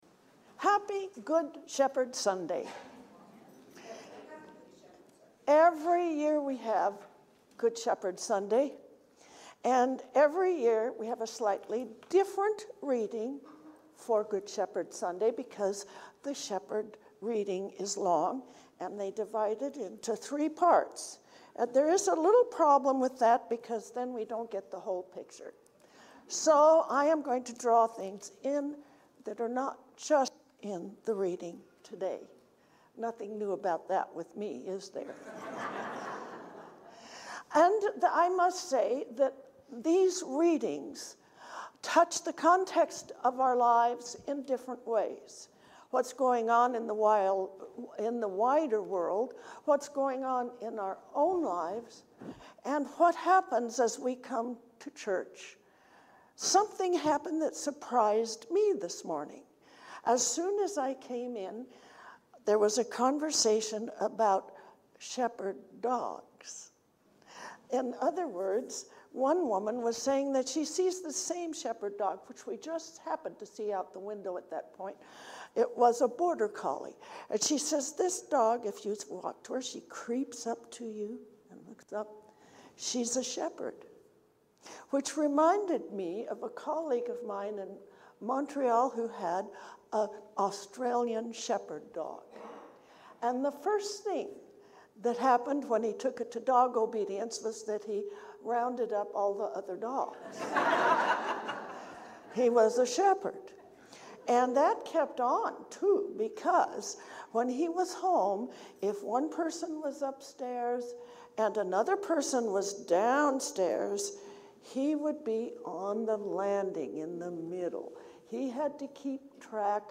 Recognizing our Good Shepherd. A sermon for Good Shepherd Sunday